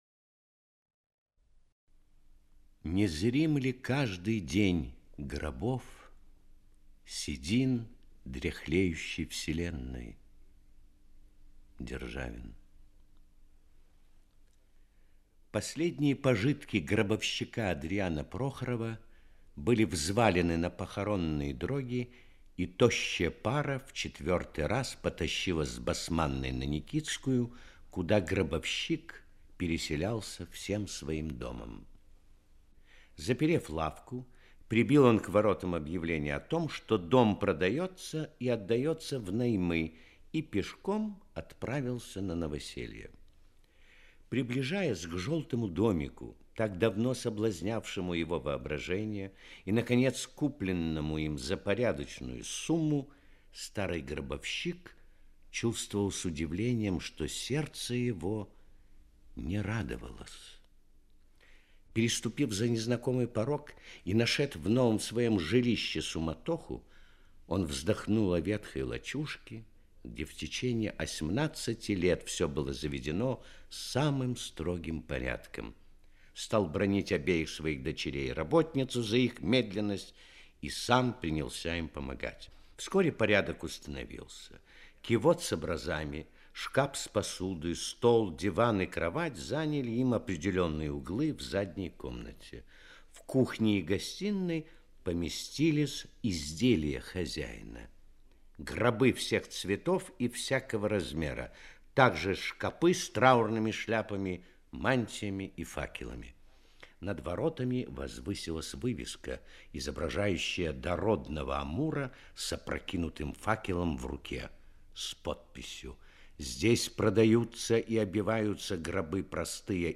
Гробовщик - аудио повесть Пушкина - слушать онлайн